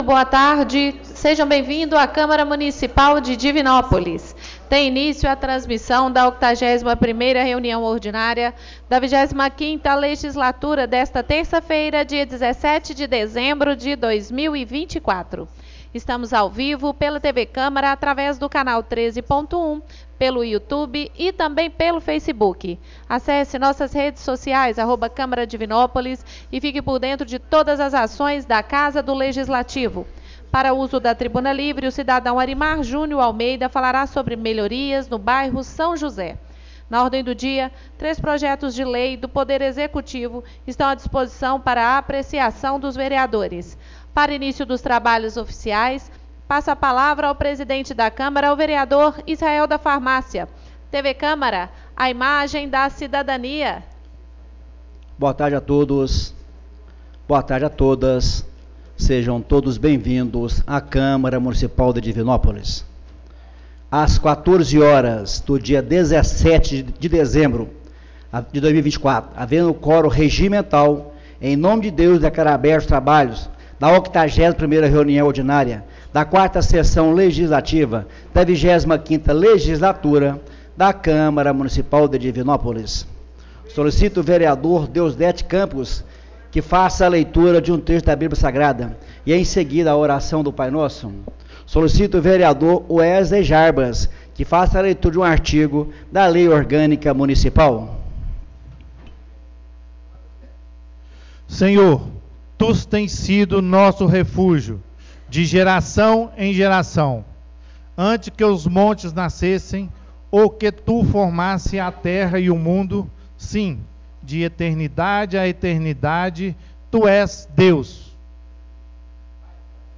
81ª REUNIÃO ORDINÁRIA - 17/12/2024